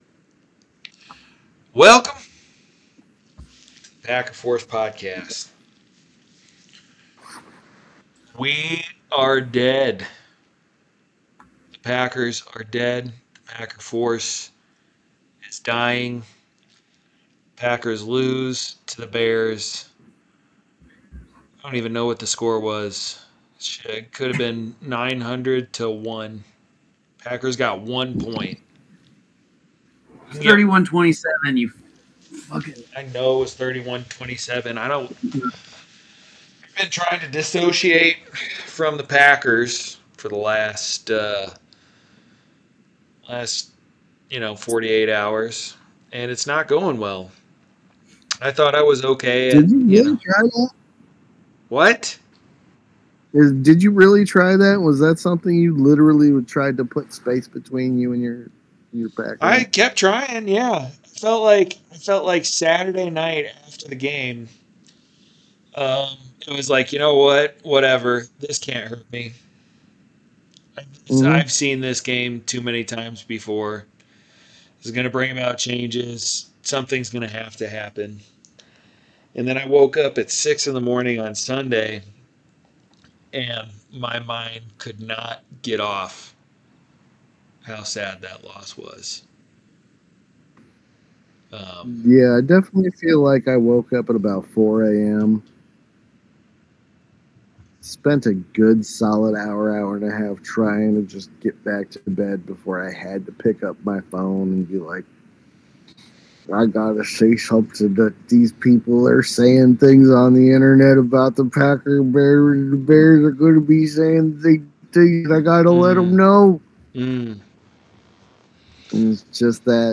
Three brothers talkin' Packers better than anyone doin' it.